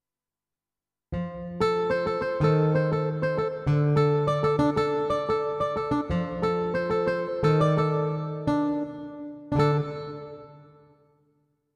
13. I SUONI - GLI STRUMENTI XG - GRUPPO "GUITAR"
XG-03-09-Steel&Body.mp3